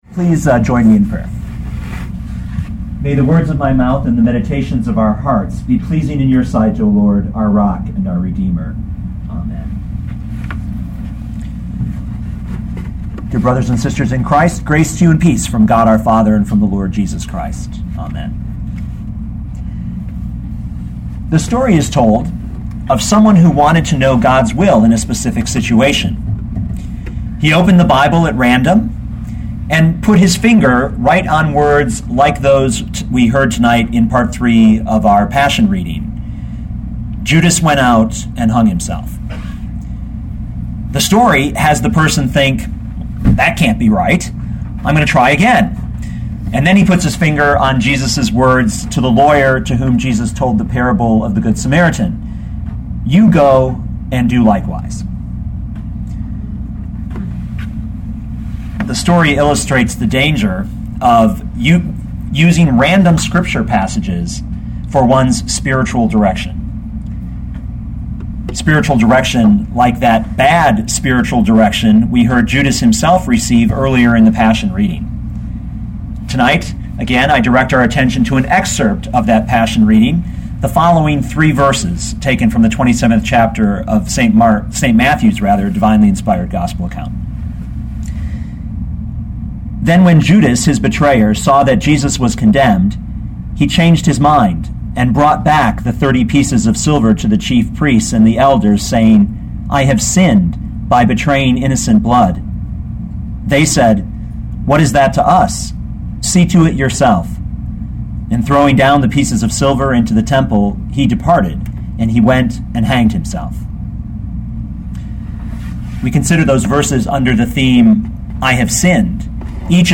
2012 Matthew 27:3-5 Listen to the sermon with the player below, or, download the audio.